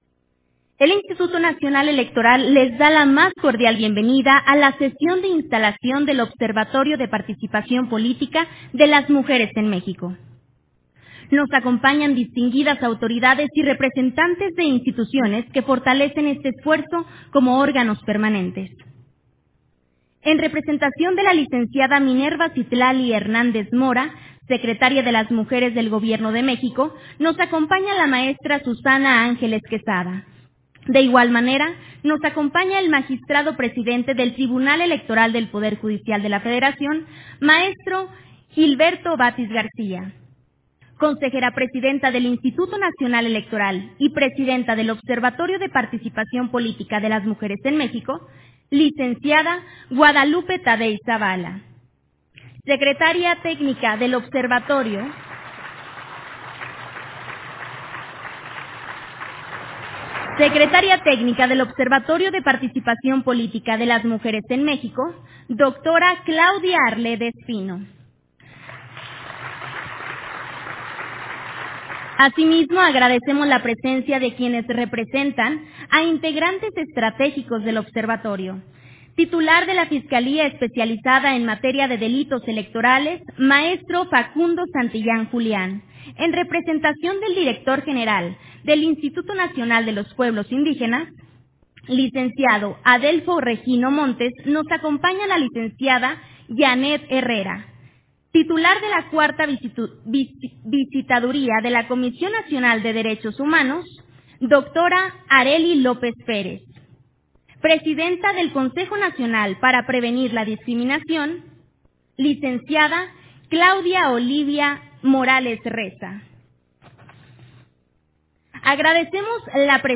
Audio de la sesión de instalación del Observatorio de Participación Política de las Mujeres en México